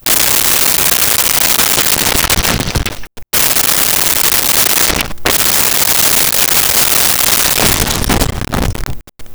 Explosion Debris Sweetener 01
Explosion Debris Sweetener 01.wav